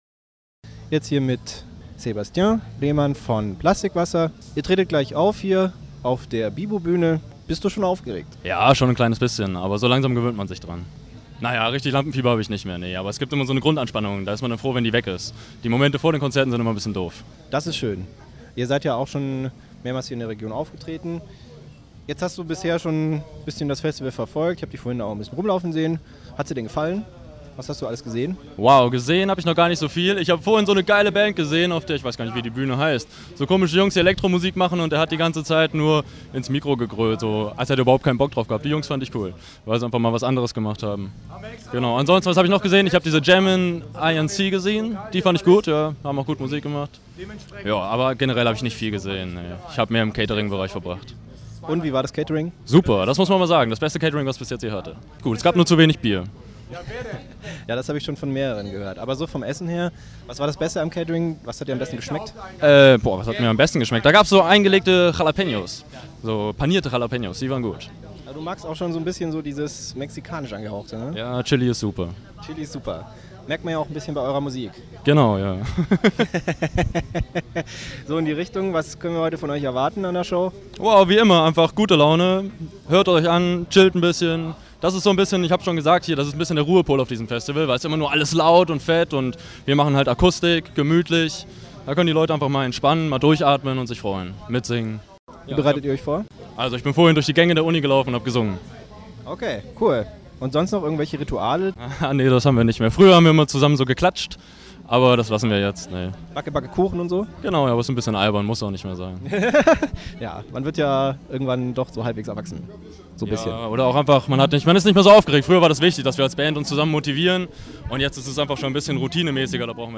Interview Plastikwasser Sommerfestival (dpm radioshow)